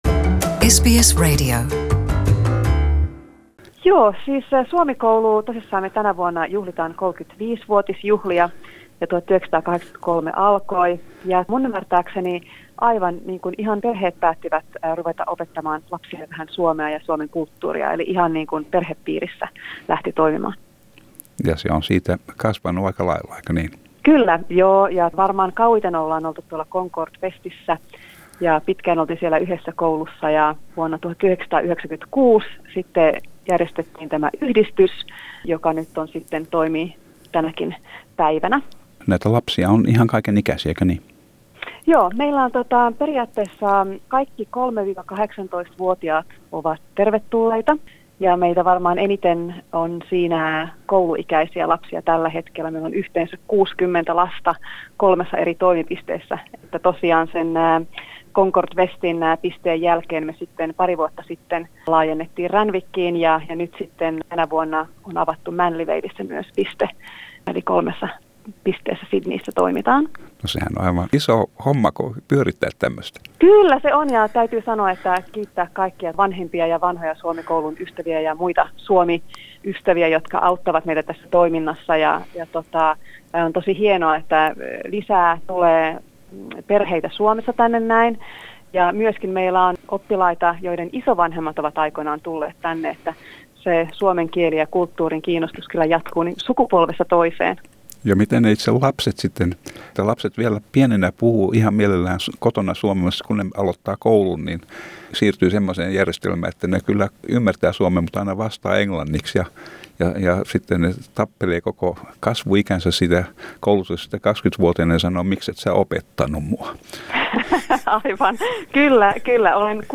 Sydneyn Suomi-koulu aloitti toimintansa vaatimattomasti mutta se on vuosien varrella menestynyt ja kasvanut, sillä on nyt kolme toimipistettä Sydneyssä. Oppilaita on kolmen vuoden ikäisistä teini-ikäisiin. Tässä haastattelussa